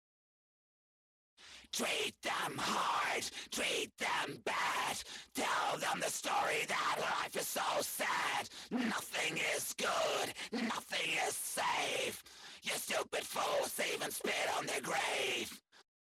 Wie bekomme ich diese Vocals ca hin.
Hallo, ich frage mich wie man diese Vocals hinbekomtm einmal von der Technik selber und einmal wie bekomme ich diesen Effekt hin also was wurde hier EQ und Distortion technisch usw gemacht?